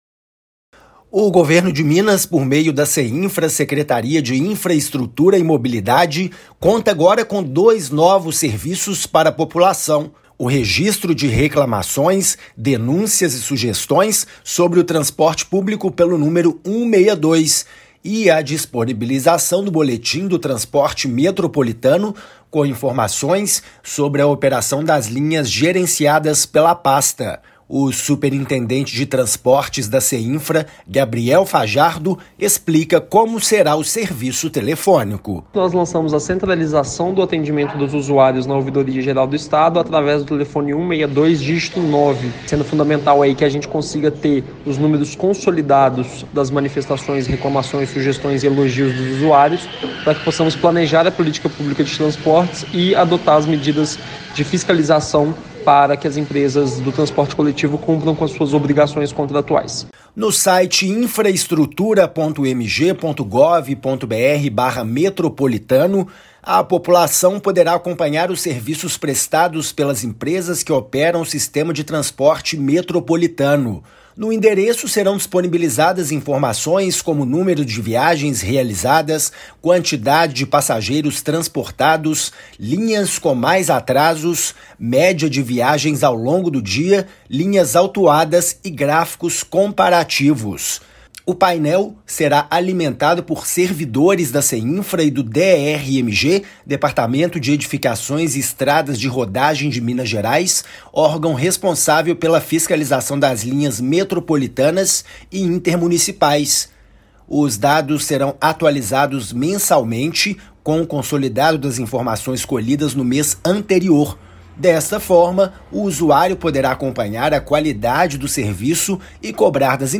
Reclamações e denúncias podem ser registradas por novo número telefônico e dados sobre a operação das linhas serão disponibilizados na internet. Ouça a matéria de rádio.
MATÉRIA_RÁDIO_NOVO_SERVIÇO_TRANSPORTE.mp3